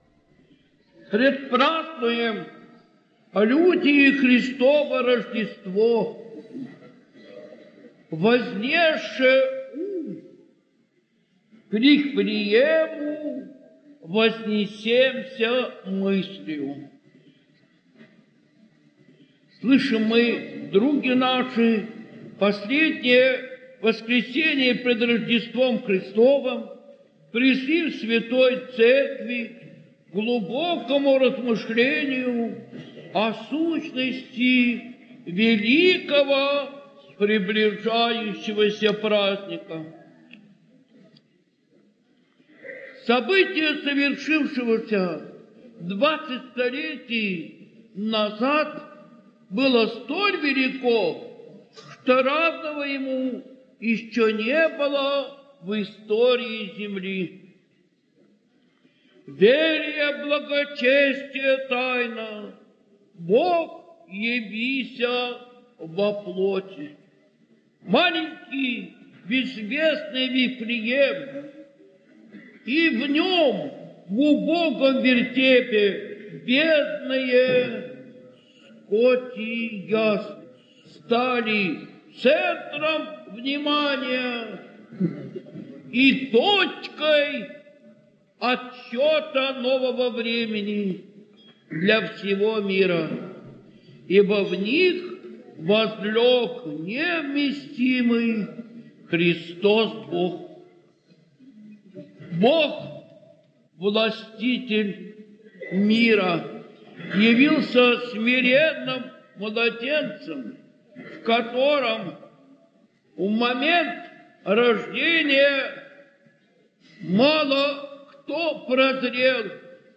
Проповедь в неделю перед Рождеством Христовым
Предлагаем вашему вниманию проповедь архимандрита Иоанна (Крестьянкина) в аудиоформате.